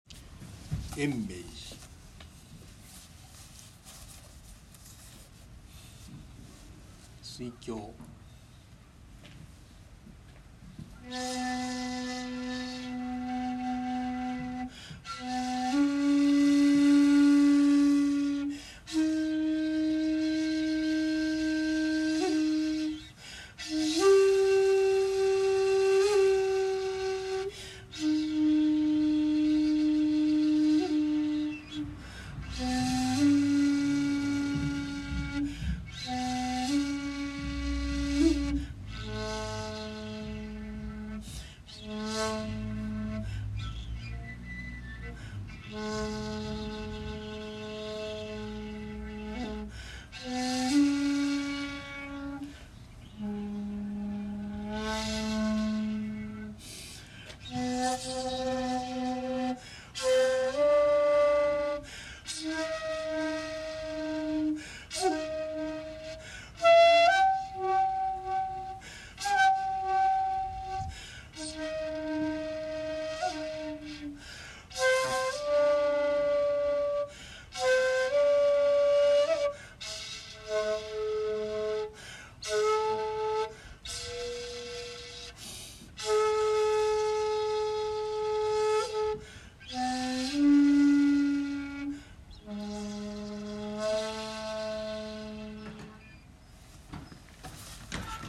今回は別格を回ってはいないのですが、今日最初の寺なのでお経をあげ、尺八を吹奏しました。
（写真②：延命寺で尺八吹奏）
518-延命寺土居町.mp3